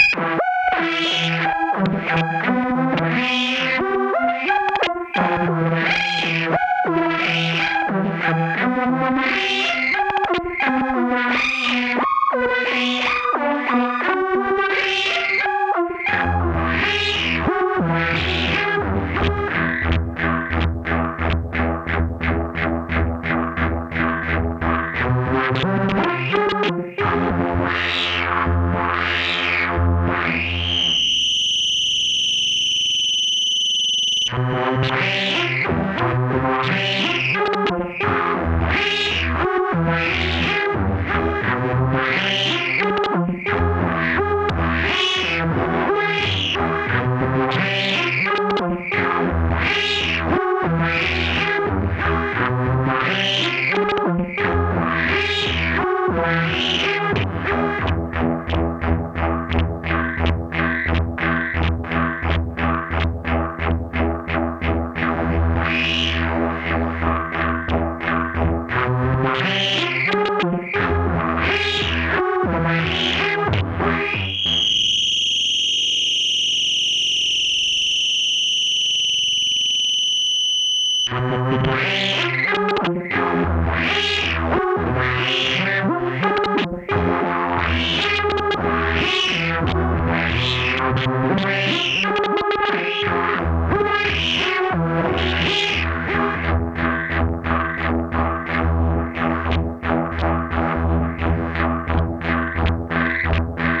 So chaos theory it is. Random Oberkorn feed by clockworks, feeding voyager, feeding Jomox t-res @ 88bpm.